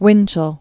(wĭnchəl), Walter 1897-1972.